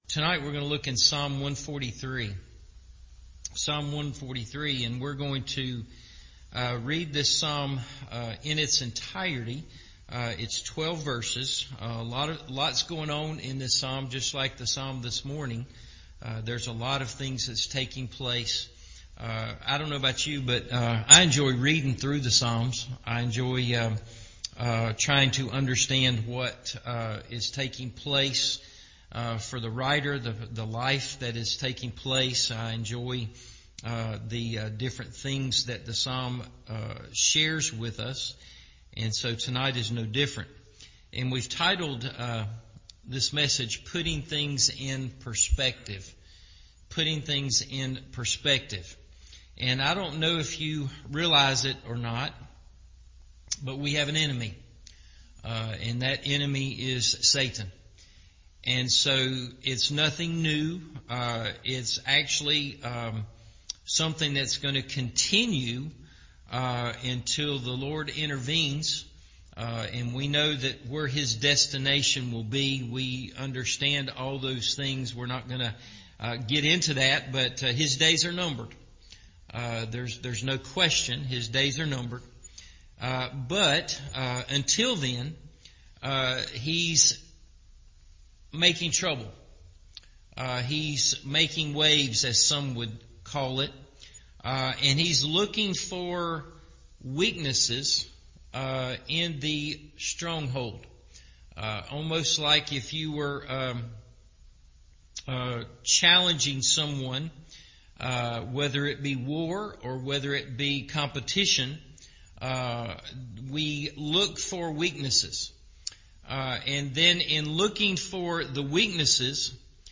Putting Things In Perspective – Evening Service